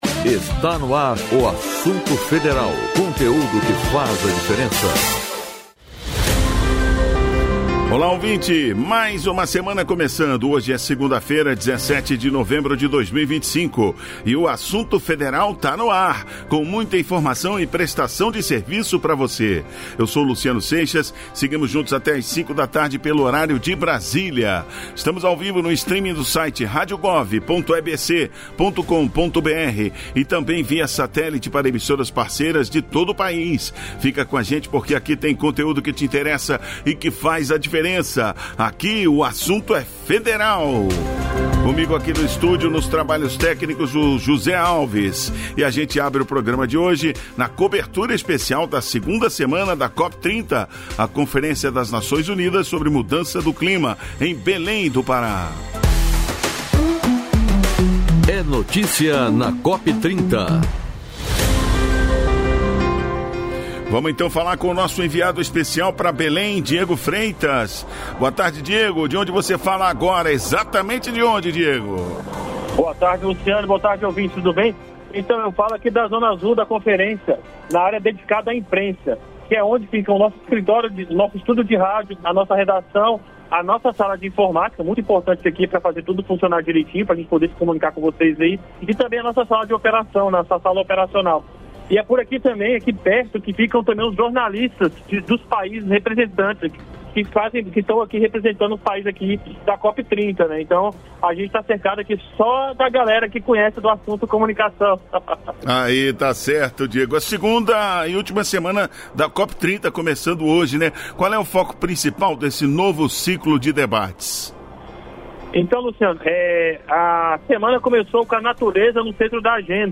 Lula discursa na abertura da Cúpula da Celac